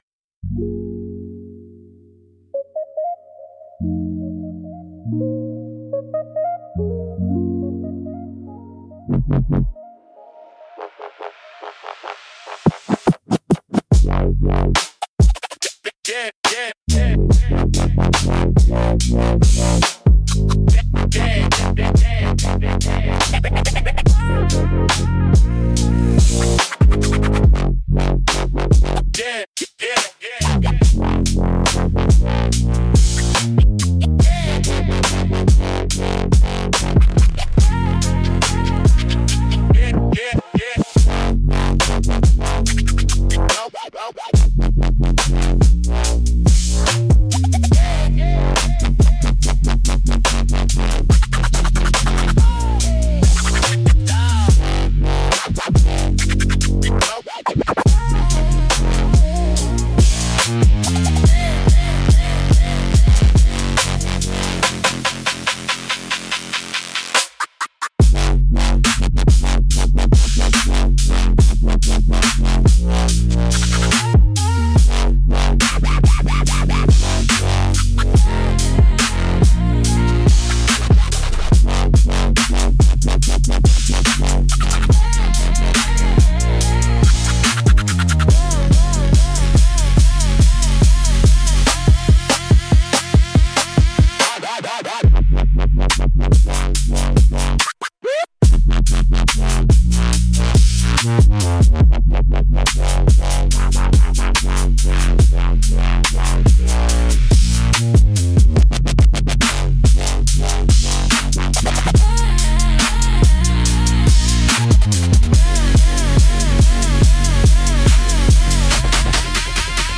Instrumentals - The Drag of 3 AM